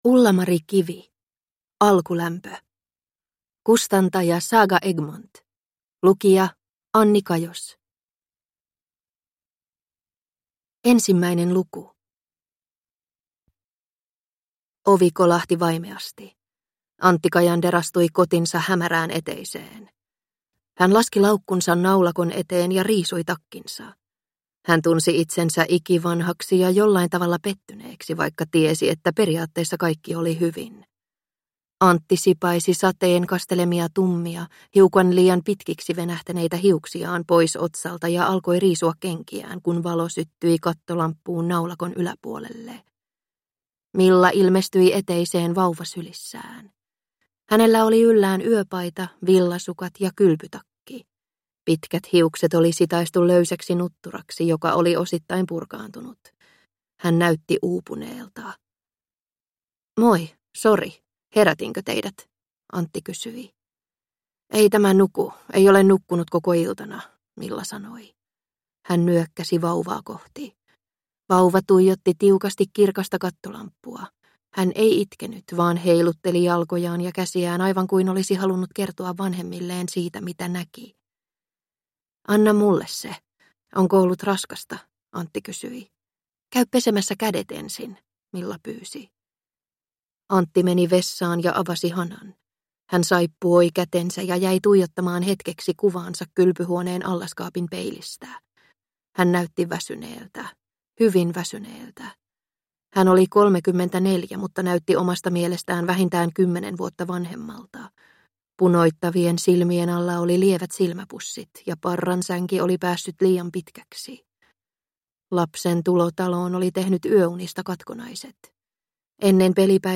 Alkulämpö – Ljudbok